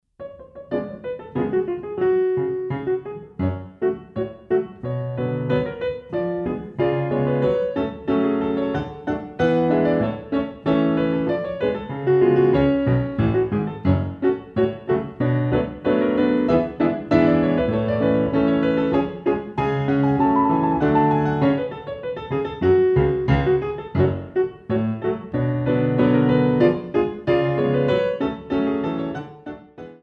Compositions for Ballet Class
Battements frappés version longue